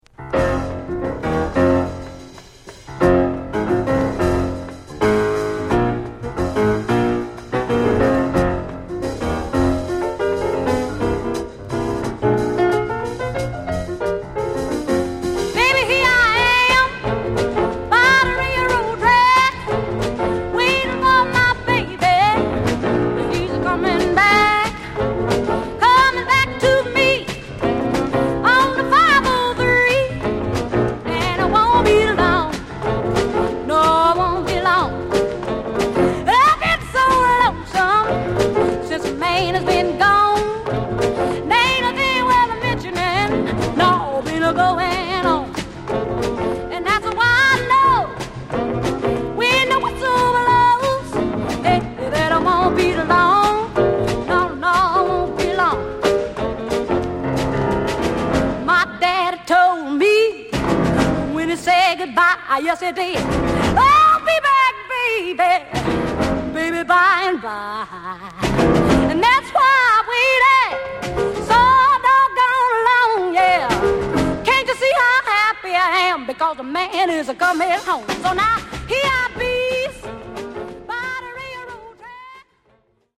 Genre: Deep Soul